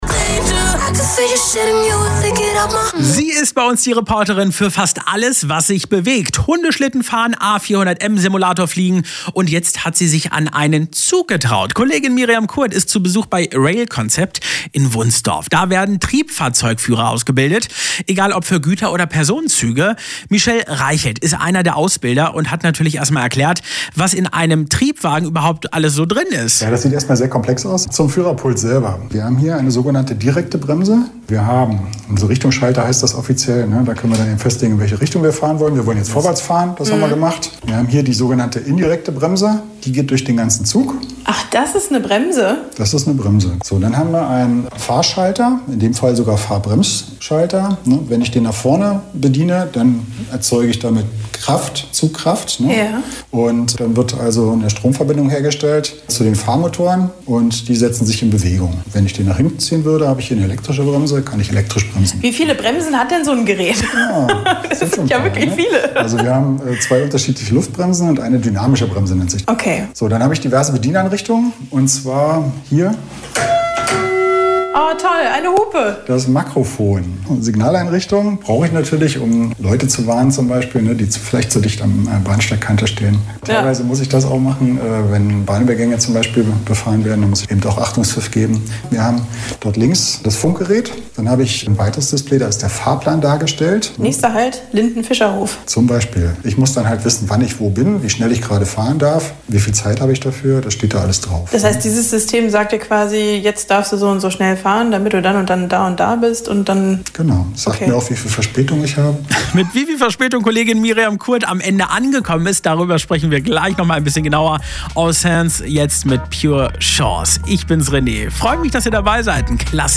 Mitschnitt_Simulator.mp3